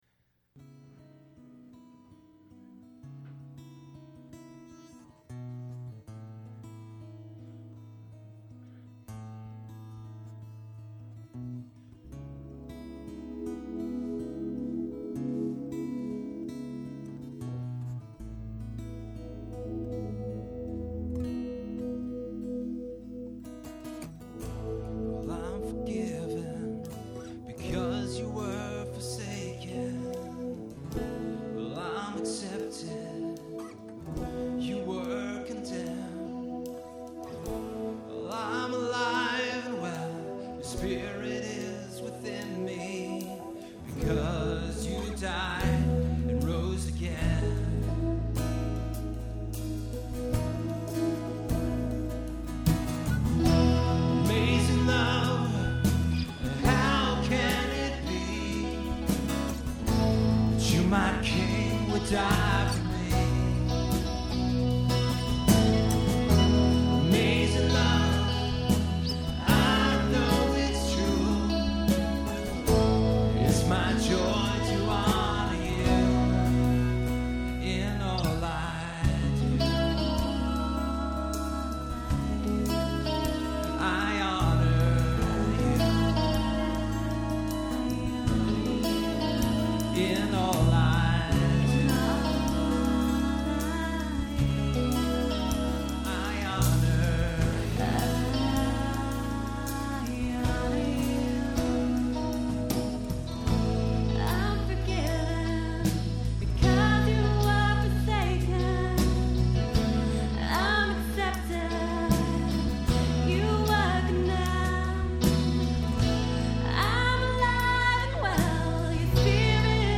Performed live at Terra Nova - Troy on 10/26/08.